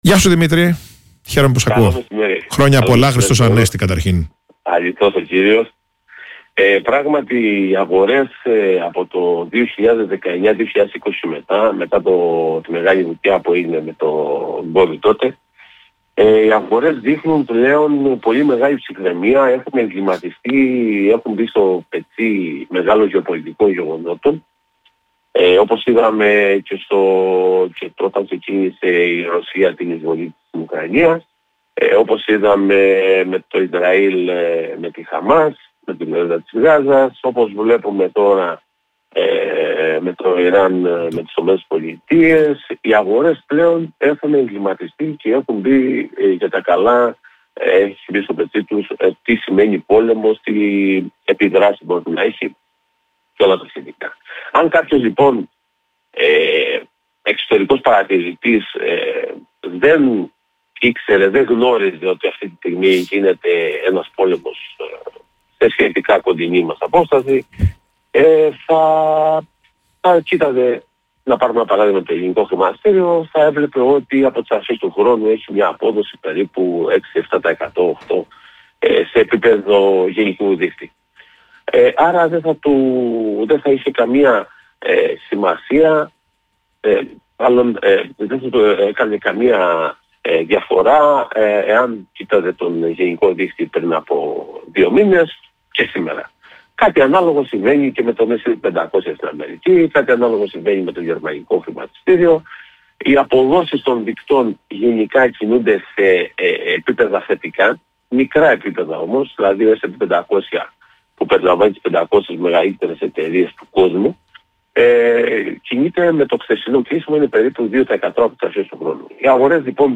στην ραδιοφωνική εκπομπή ΠΥΞΙΔΑ του Politica